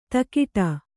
♪ takiṭa